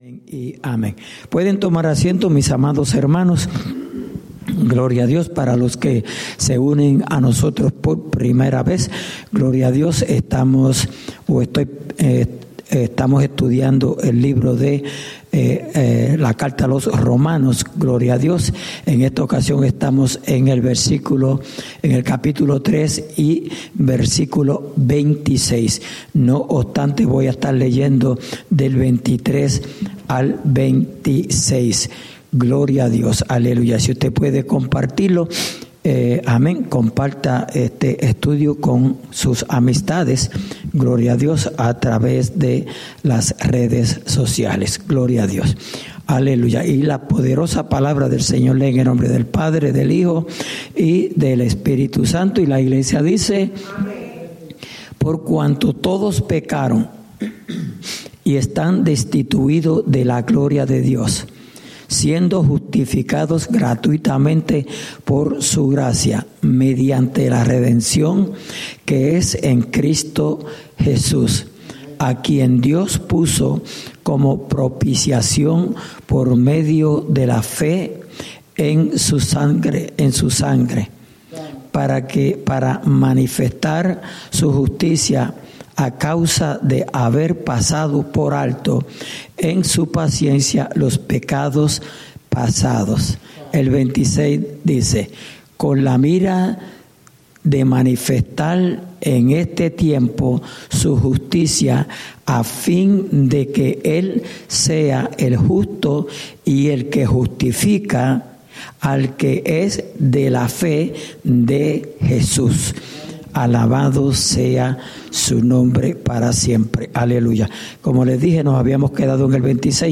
Estudio Bíblico: Libro de Romanos (Parte 8)